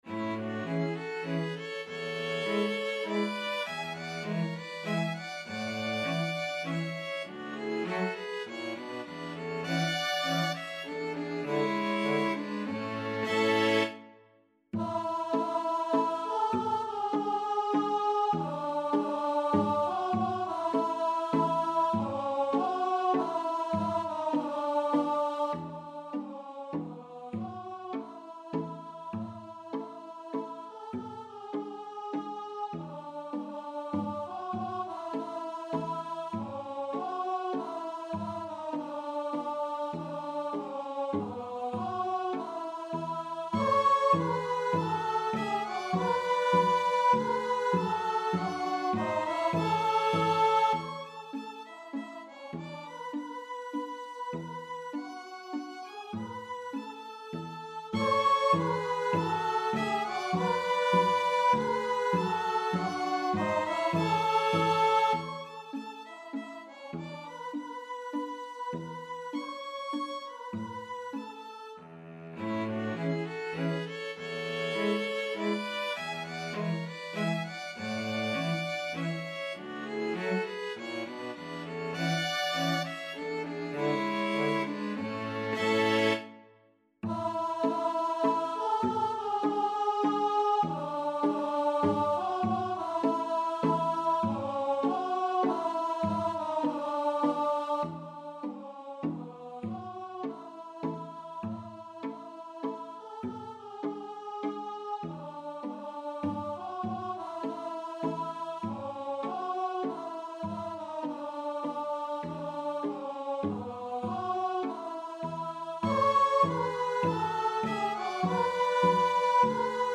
Santa Lucia Free Sheet music for Voice and String Quartet